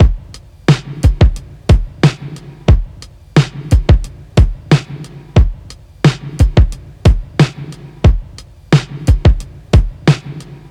• 90 Bpm Drum Beat E Key.wav
Free drum groove - kick tuned to the E note. Loudest frequency: 487Hz
90-bpm-drum-beat-e-key-q4T.wav